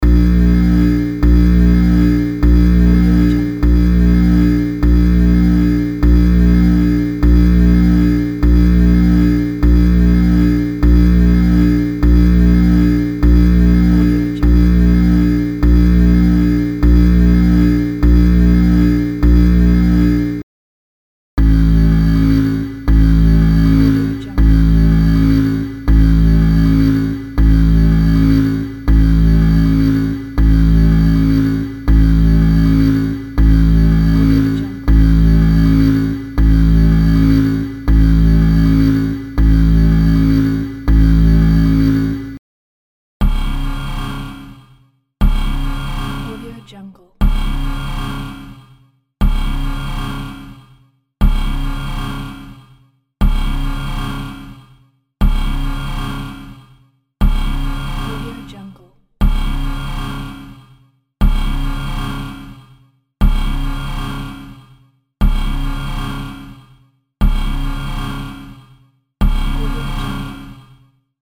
دانلود افکت صدای آلارم هشدار
افکت صدای آلارم هشدار یک گزینه عالی برای هر پروژه ای است که به صداهای بازی و جنبه های دیگر مانند زنگ هشدار، بیگانه و برنامه نیاز دارد.
Sample rate 16-Bit Stereo, 44.1 kHz
Looped Yes